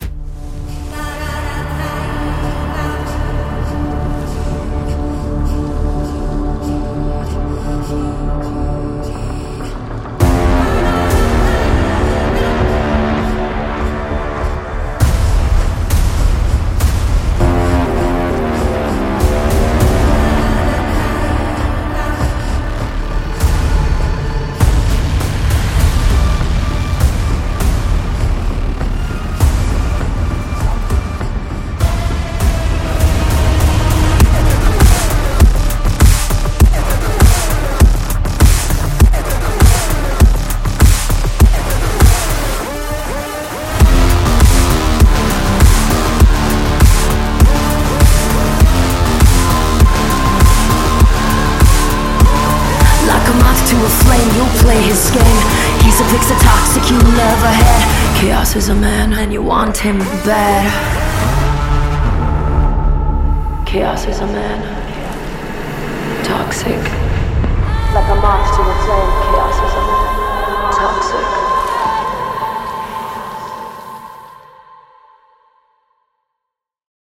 Action, Power packed, high energy, swag, intense, BGM